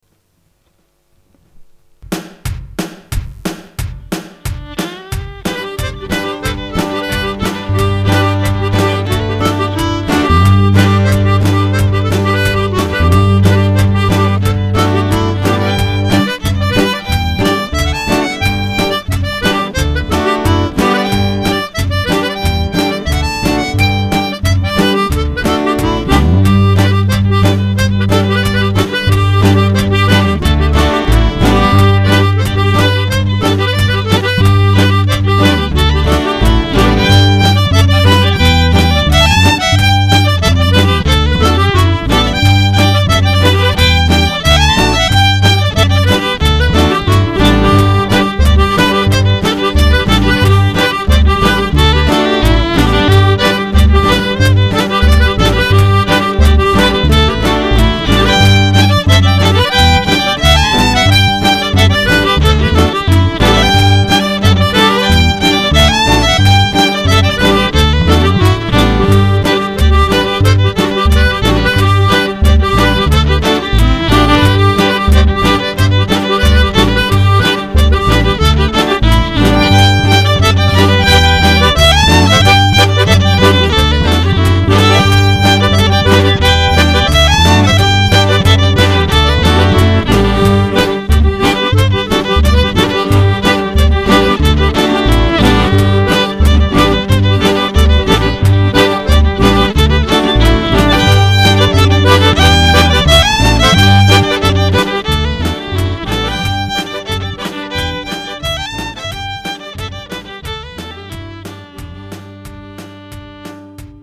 Shenanigan, The Celtic Party Band out for the craic
Irish & Party music in The South of England.